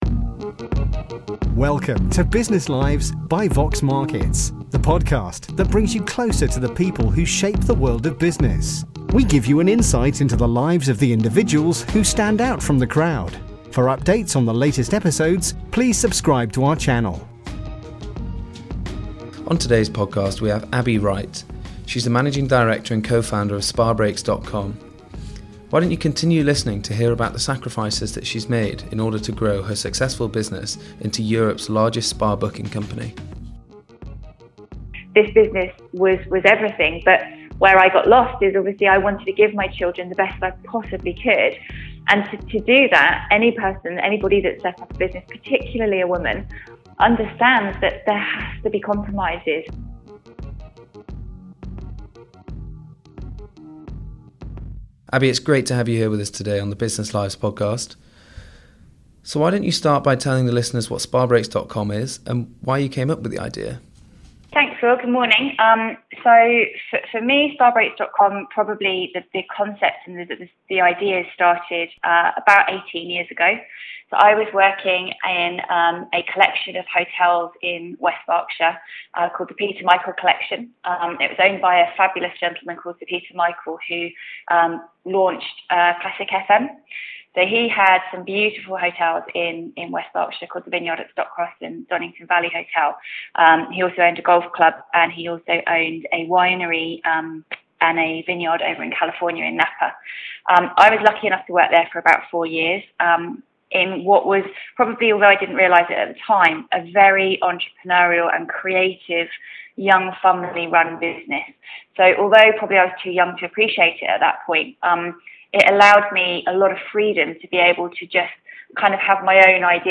personal interview